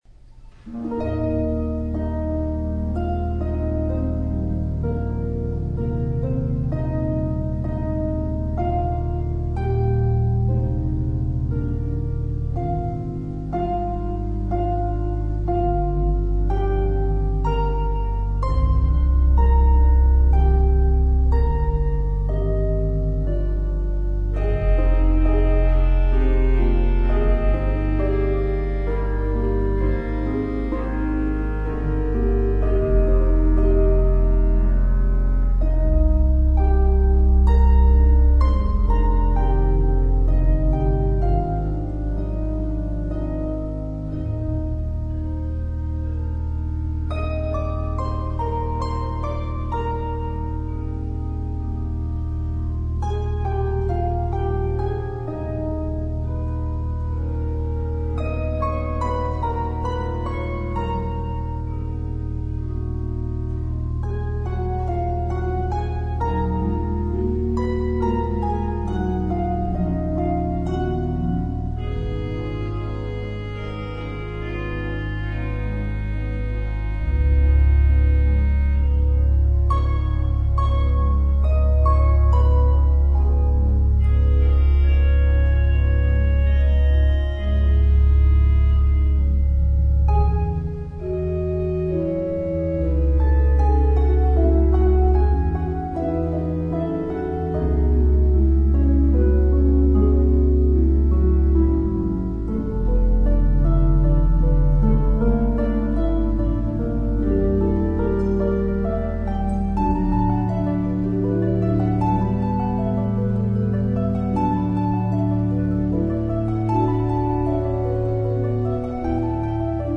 Voicing: Harp and Organ